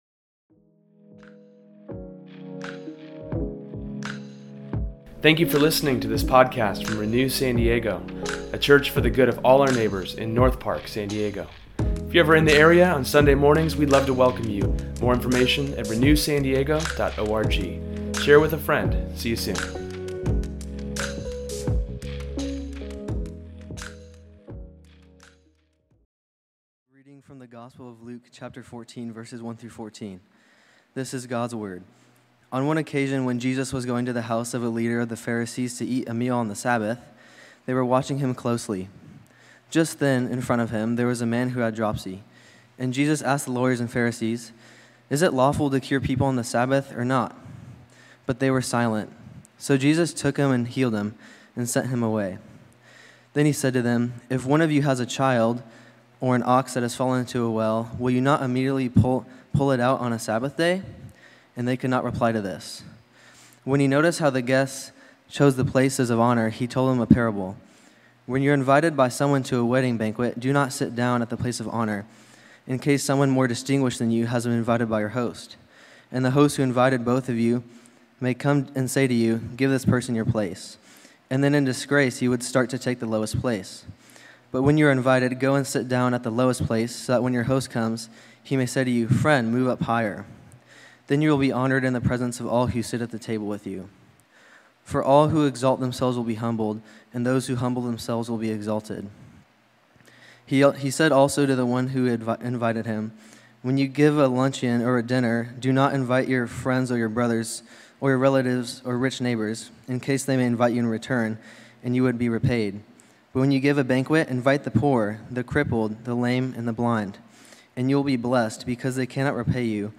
renewsermon831.mp3